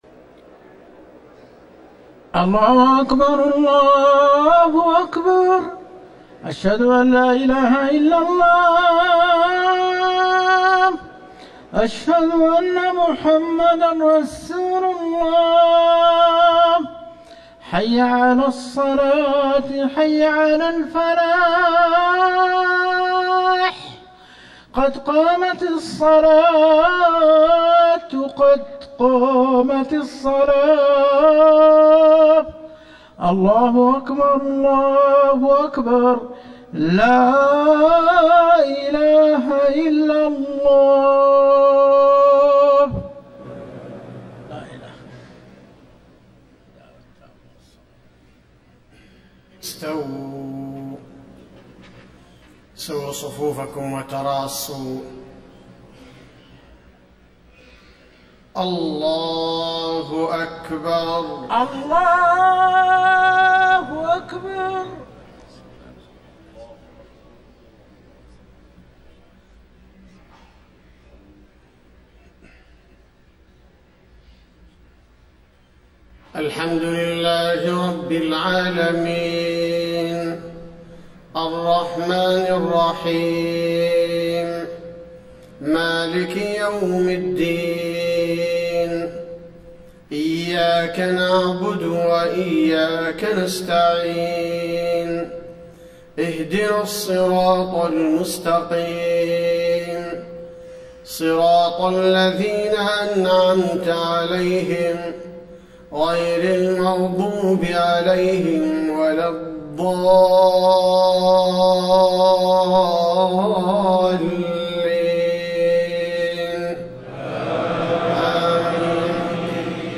صلاة الفجر 2-9-1434 من سورة الفرقان > 1434 🕌 > الفروض - تلاوات الحرمين